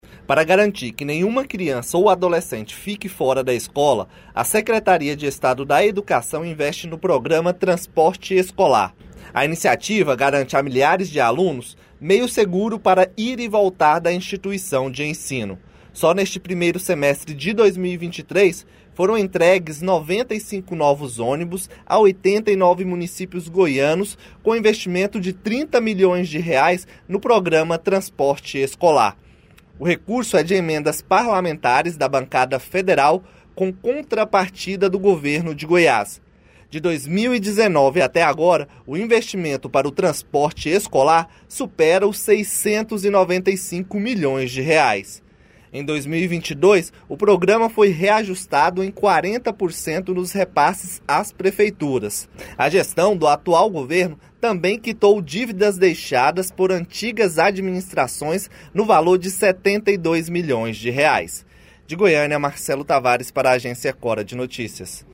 Repórter